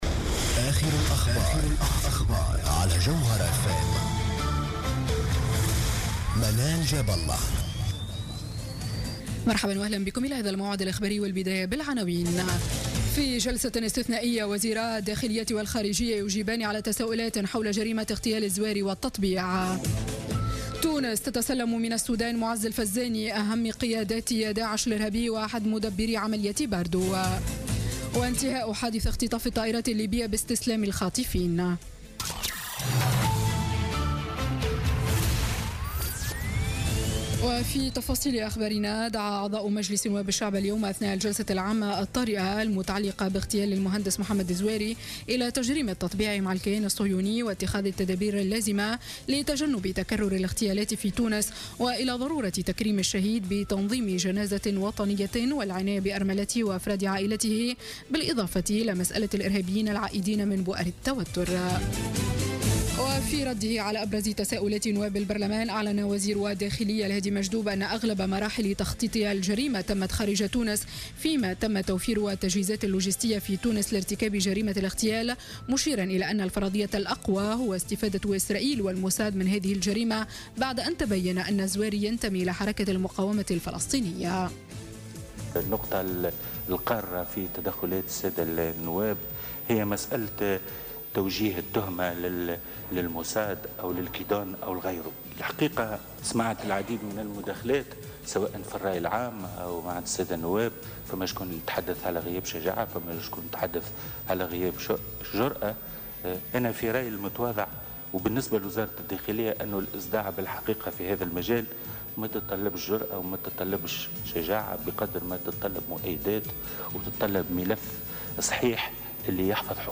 نشرة أخبار السابعة مساء ليوم الجمعة 23 ديسمبر 2016